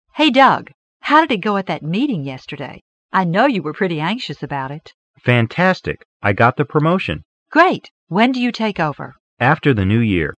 办公室英语会话第173期--Getting a promotion 获得提升